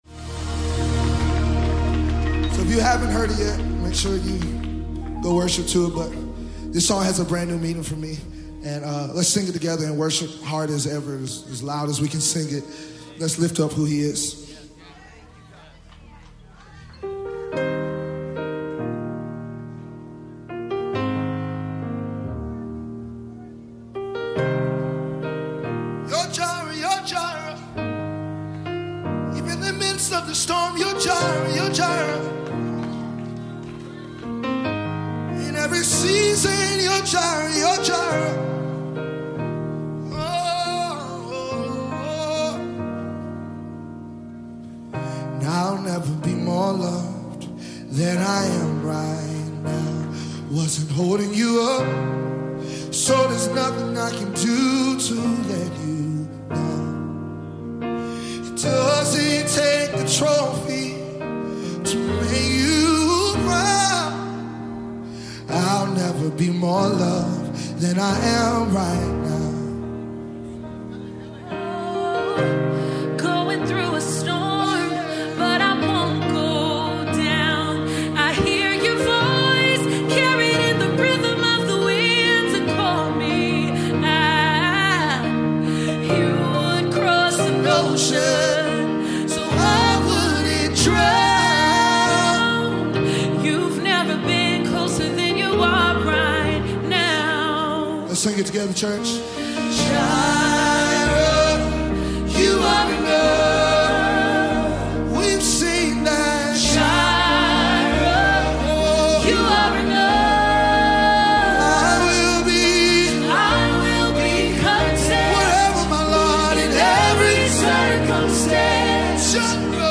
medley dubbed from a Sunday service